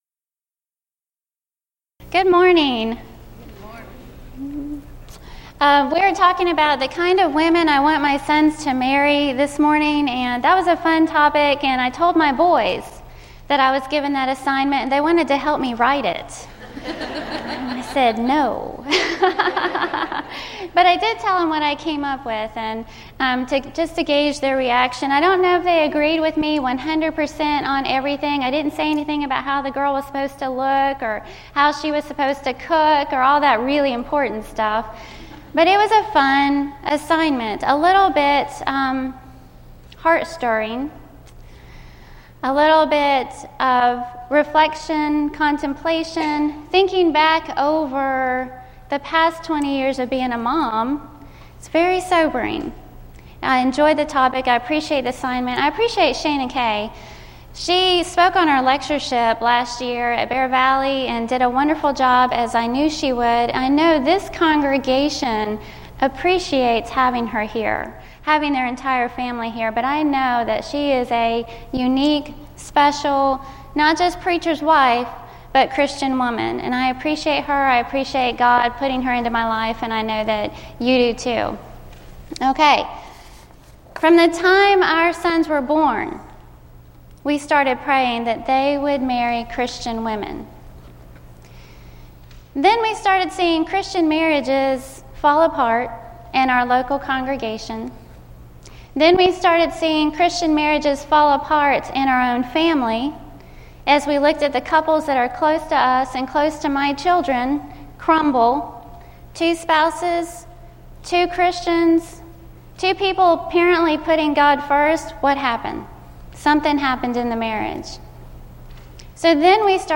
Event: 2014 Focal Point
lecture